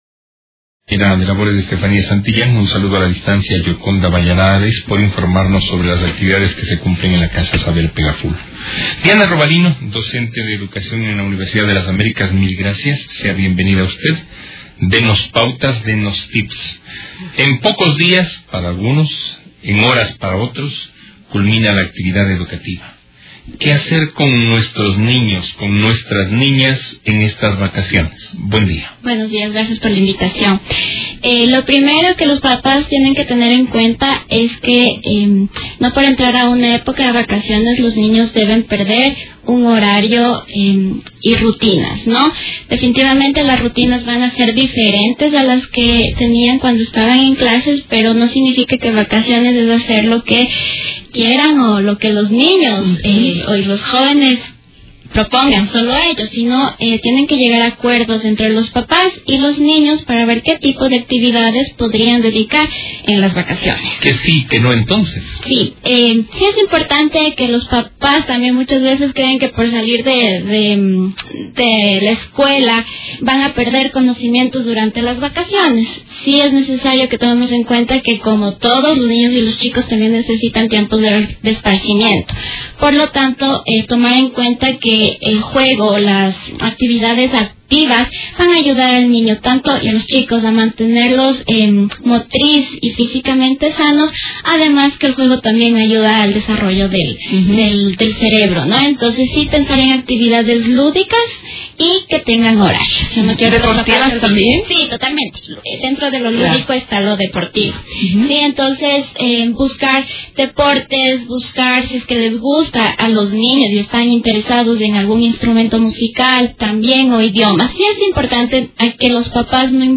durante una entrevista en radio Distrito